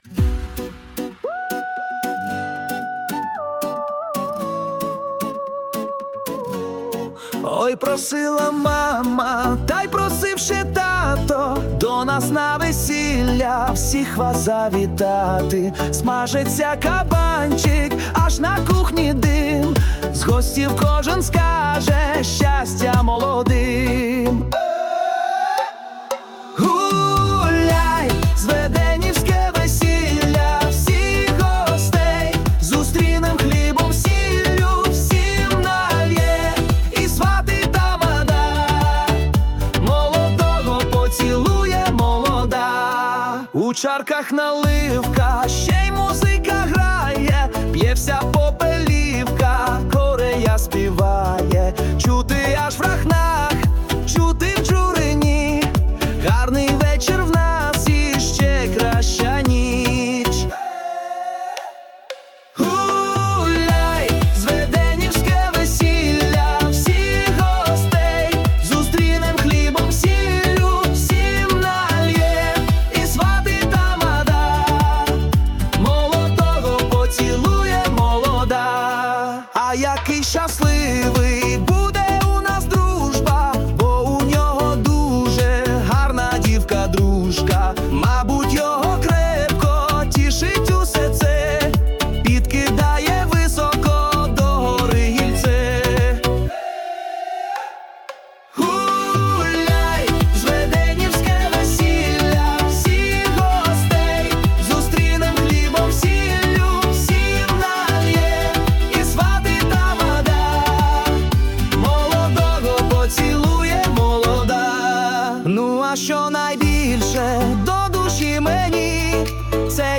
Весела пісня!